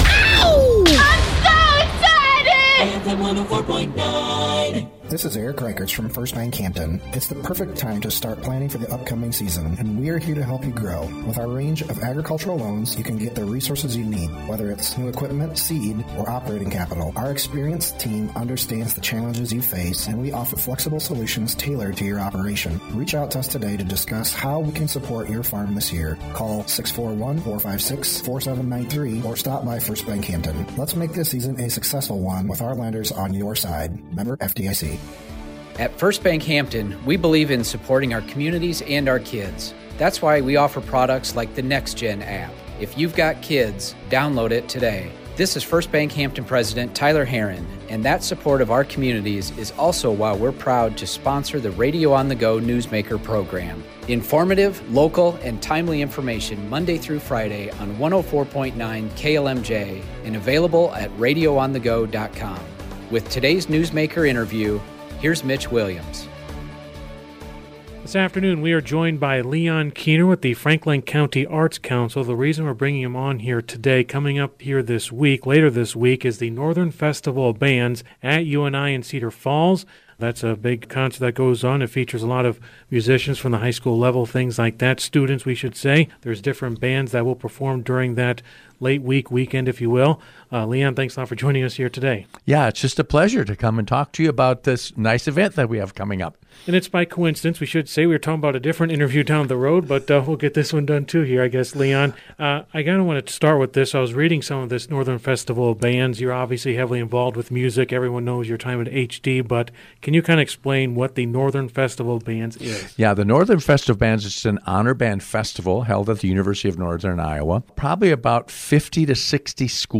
Full interview below